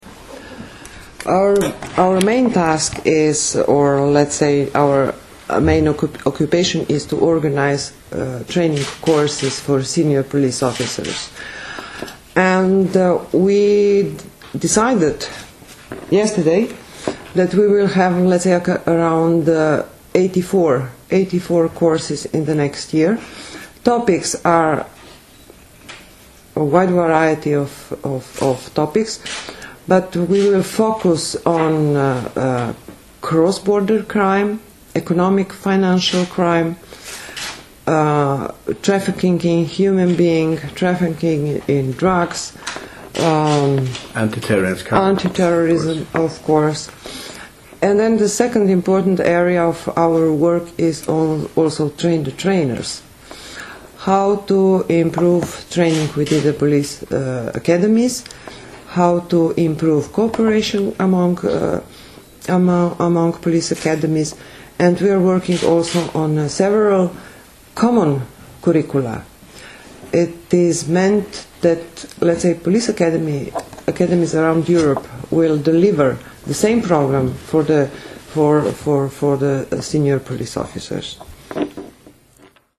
Statement about CEPOL Main Tasks in 2009